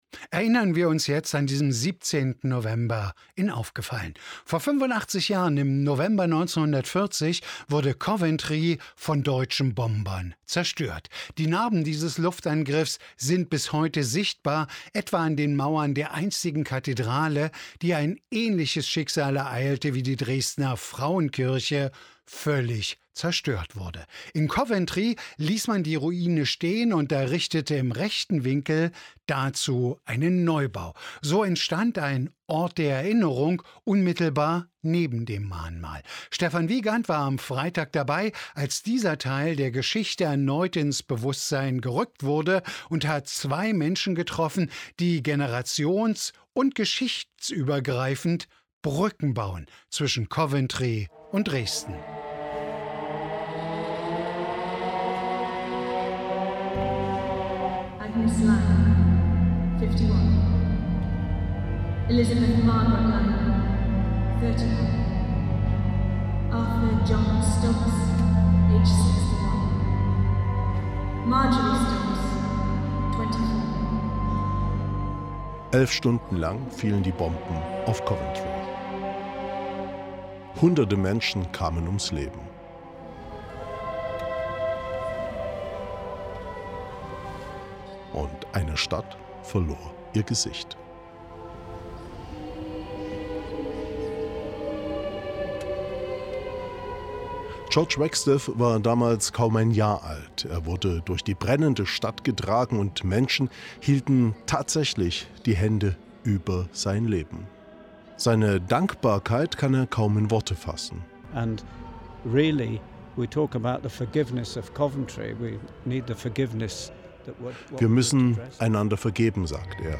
MDR-Radiobeitrag vom 17.11.2025 über Coventry
zum Radiobeitrag